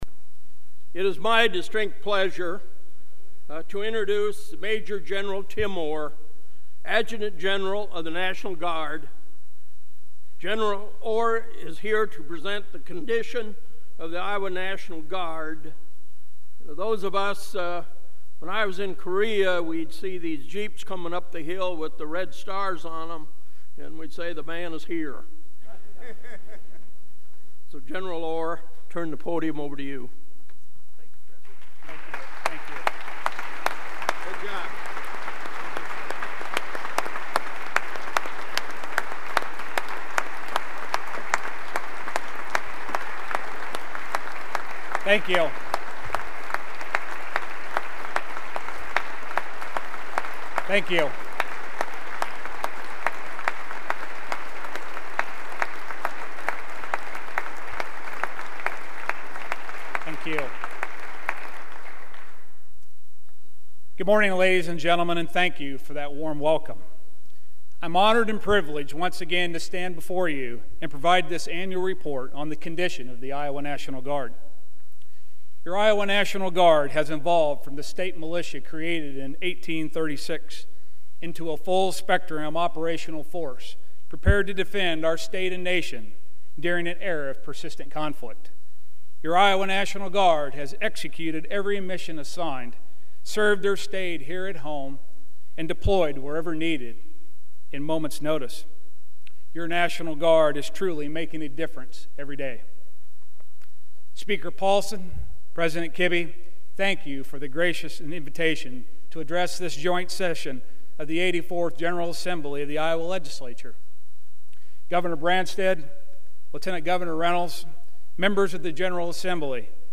The leader of the Iowa National Guard says due to multiple deployments, the members of the units he oversees are the “most seasoned” soldiers in the Guard’s 170-year history. Adjutant General Tim Orr delivered the annual “Condition of the Guard” address to legislators this morning.